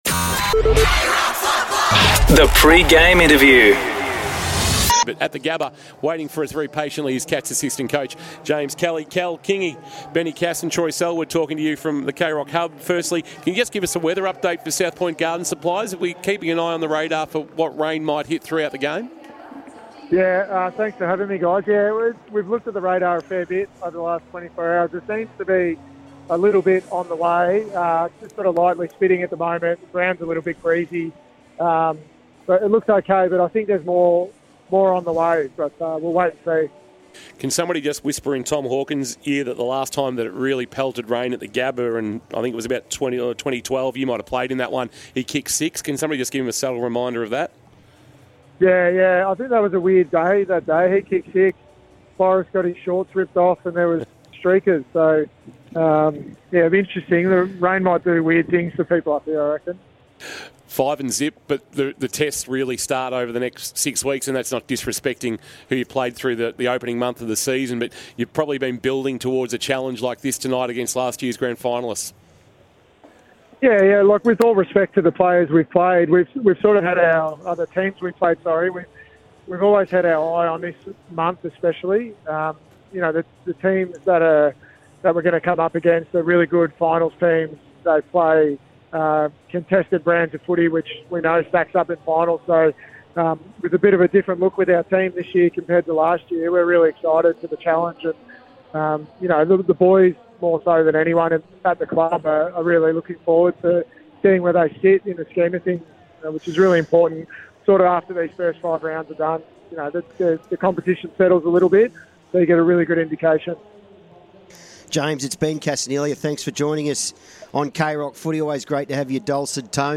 2024 - AFL - Round 6 - Brisbane vs. Geelong: Pre-match interview